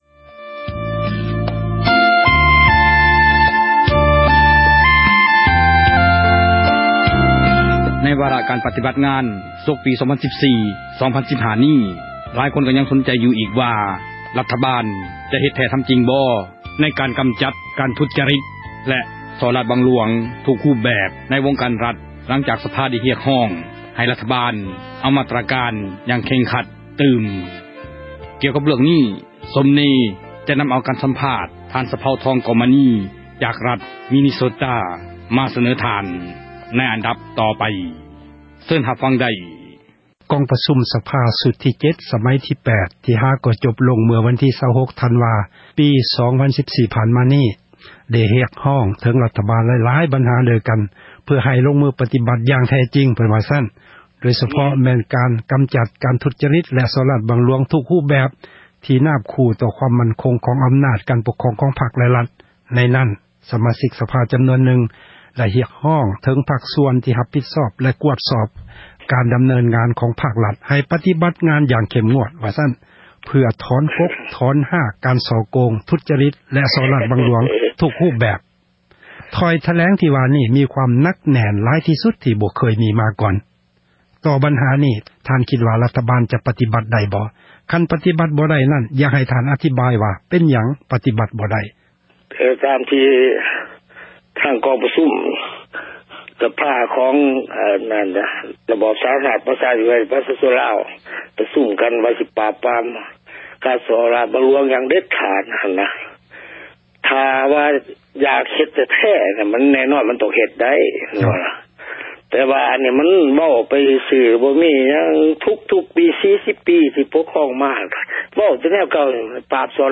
ການ ສັມພາດ